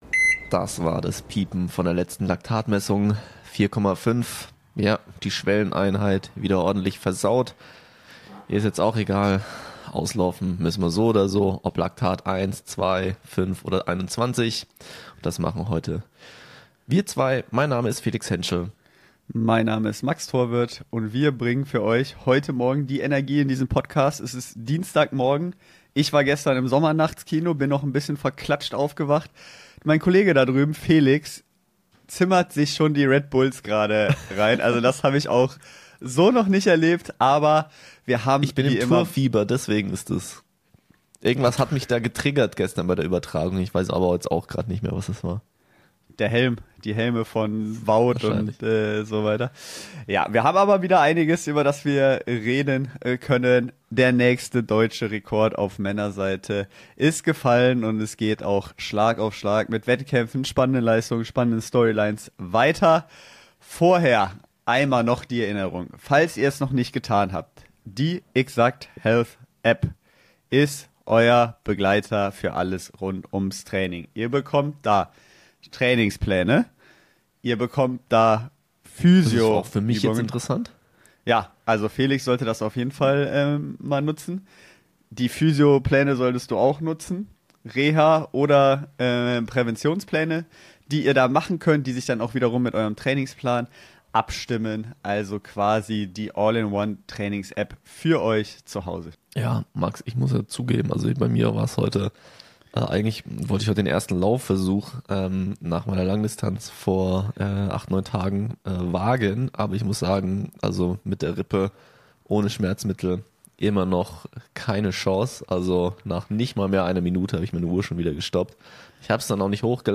Weil wir uns nicht einigen konnten, auf welcher Yacht wir diese Auslaufen Podcast Folge aufnehmen sollen, sind wir dann doch in unsere eigenen Studios gefahren.